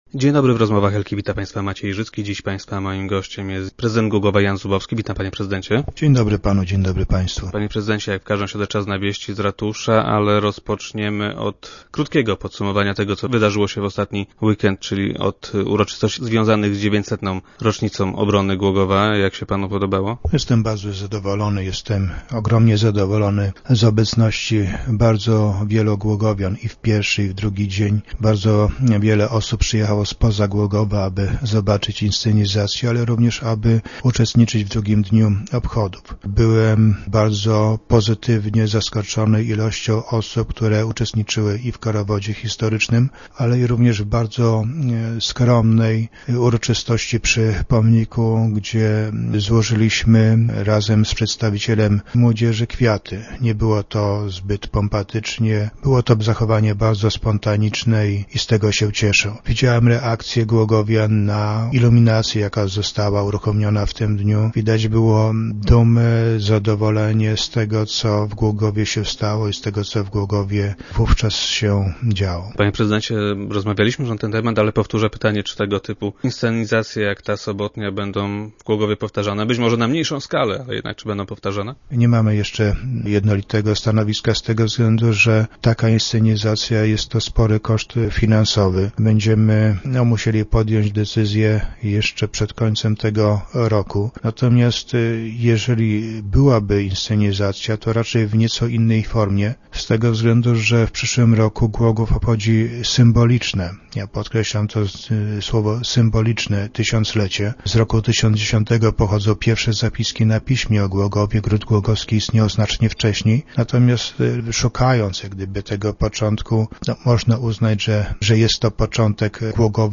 Jak twierdzi Jan Zubowski - prezydent Głogowa i gość dzisiejszych Rozmów Elki, zmiany te są konieczne.